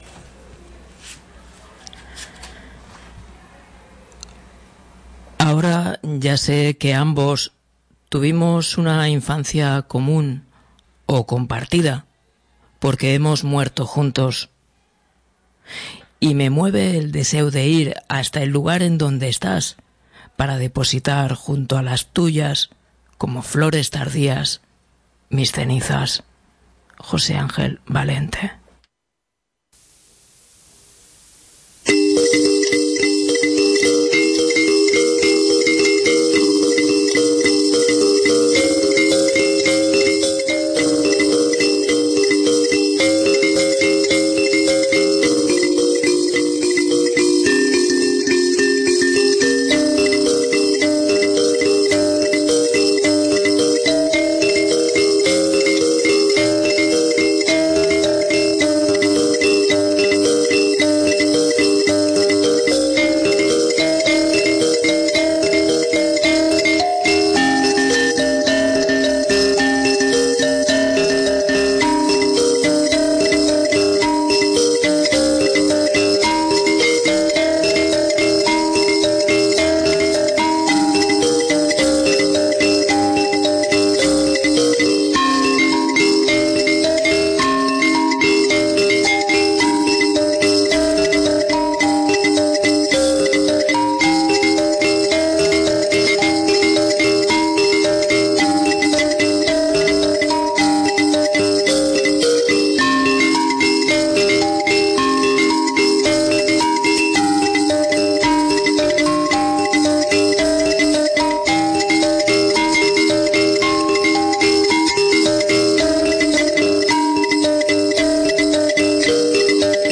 Revisitamos también su primer libro de poemas y alguna cosa más -también en su propia voz-.
Iniciamos sus lecturas con un par de cuentos, fragmentos de otros tantos y una breve semblanza sobre él.